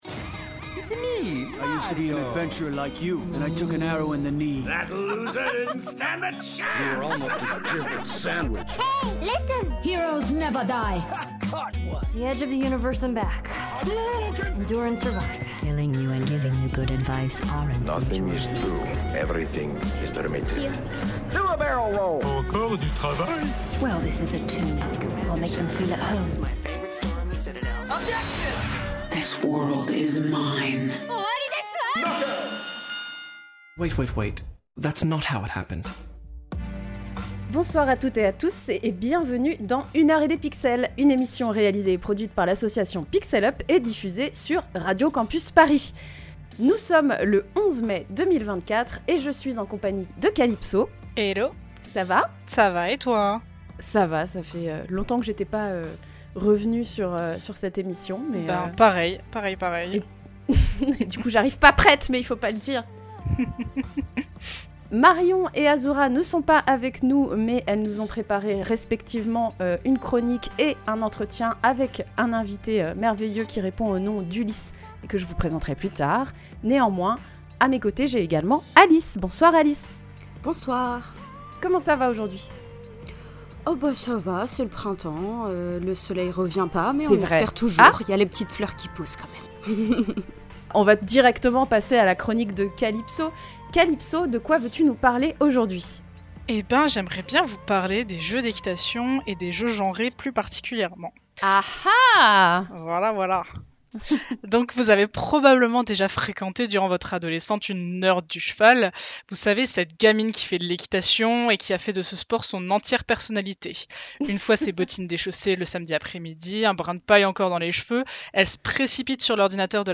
Émission diffusée le 19 mai 2024 sur Radio Campus Paris.